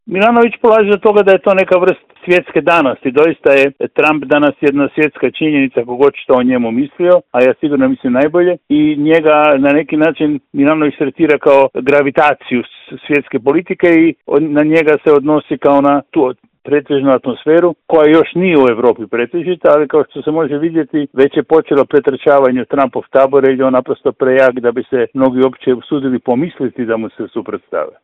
Razgovor je za Media servis komentirao politički analitičar Žarko Puhovski: